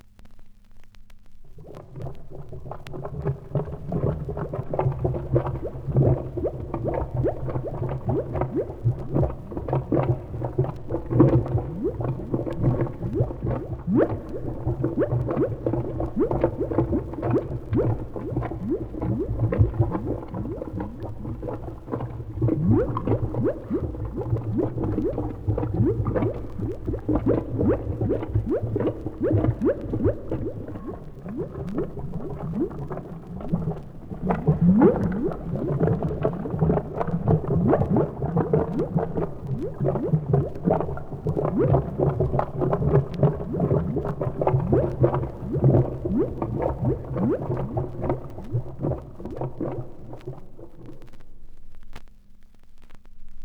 • boiling oil - vinyl sample.wav
Recorded from Sound Effects - Death and Horror rare BBC records and tapes vinyl, vol. 13, 1977.
boiling_oil_-_vinyl_sample_Fuq.wav